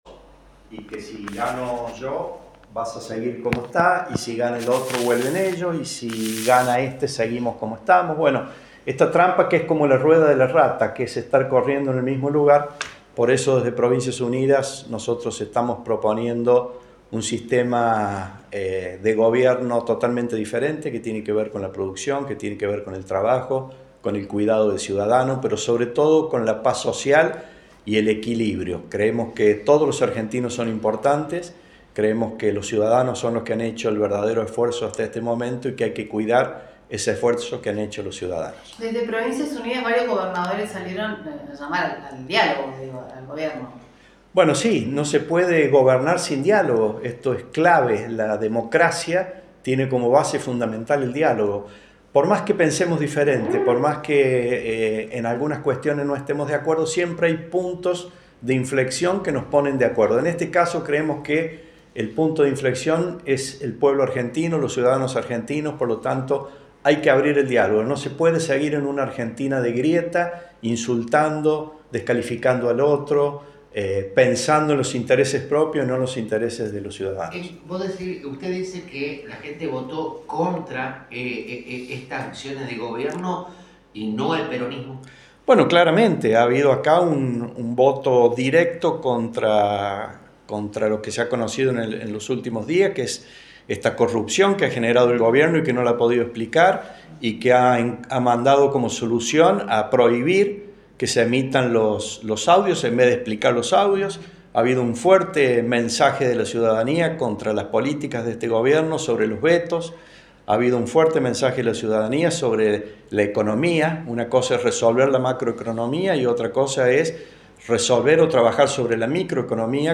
brindaron una conferencia de prensa para los medios locales